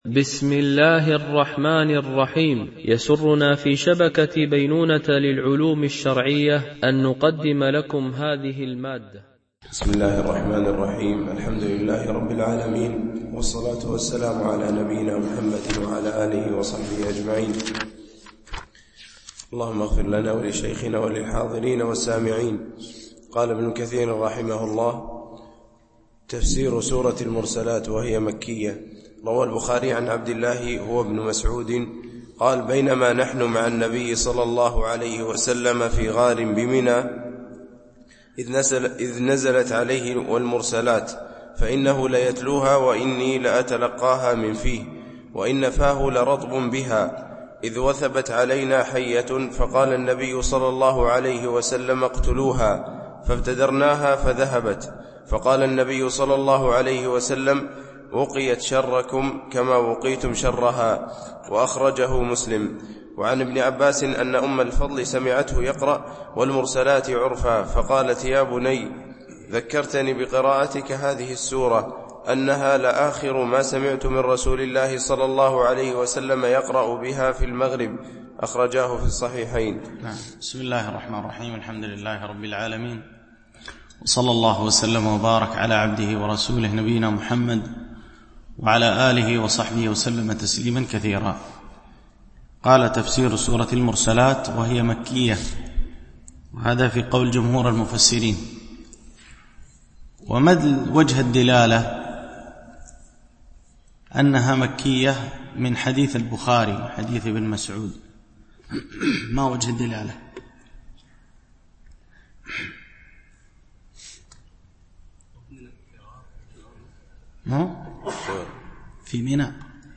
شرح مختصر تفسير ابن كثير(عمدة التفسير) الدرس 73 (سورة االمرسلات)